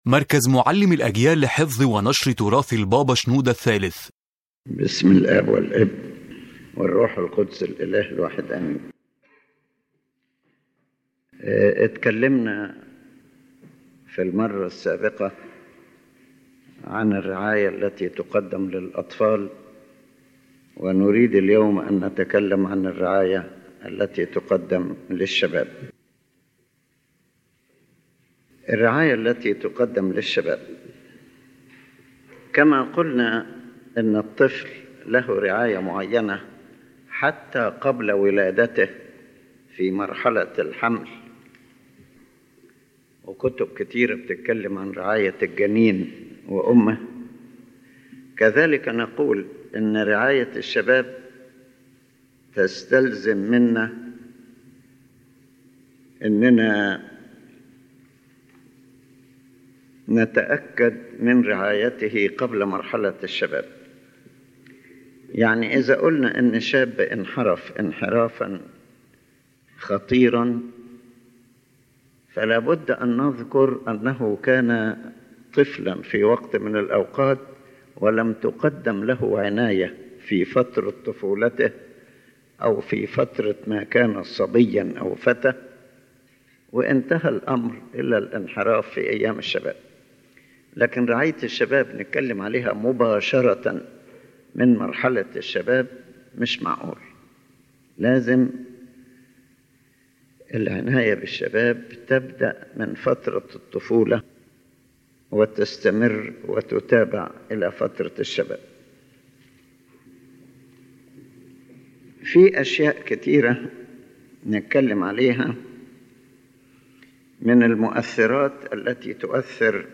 أولًا: الفكرة الأساسية للمحاضرة